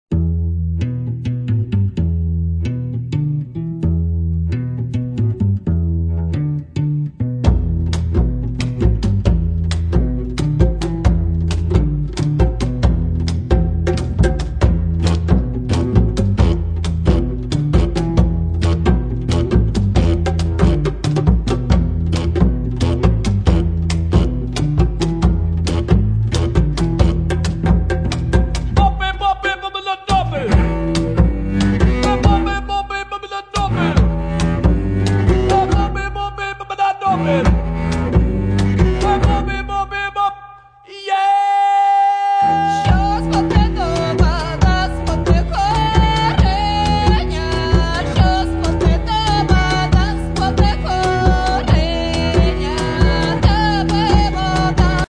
Народна (248)
Етно гурт